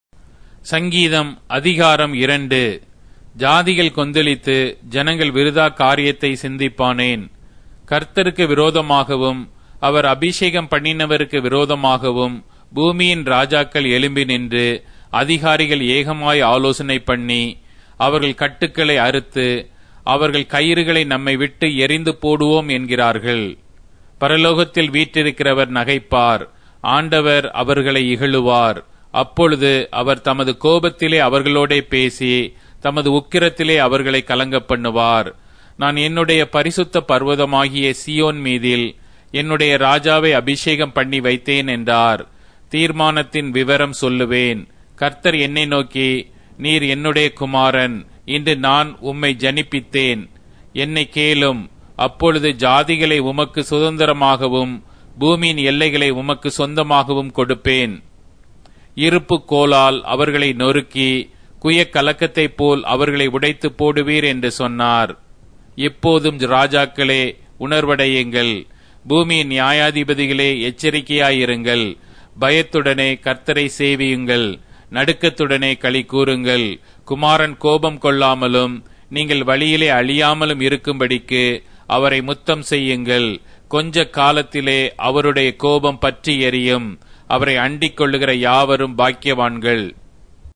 Tamil Audio Bible - Psalms 138 in Ncv bible version